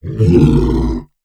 MONSTER_Growl_Medium_18_mono.wav